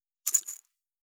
３７５薬を振る,薬瓶,薬瓶振る,
効果音